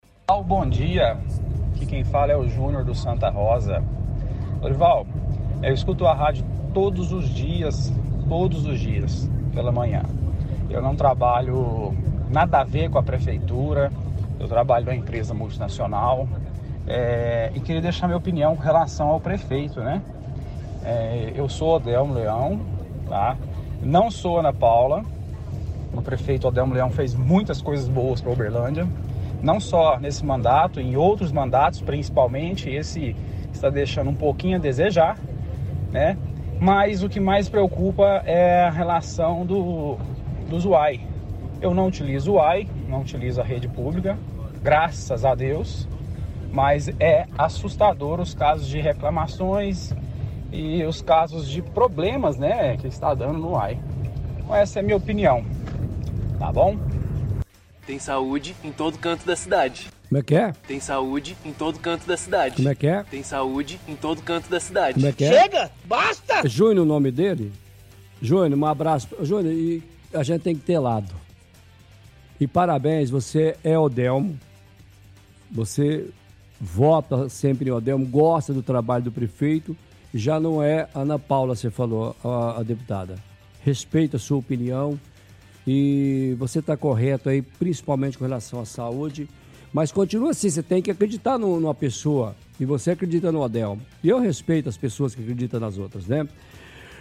– Transmite trechos de áudios da propaganda da prefeitura utilizados para fazer deboches.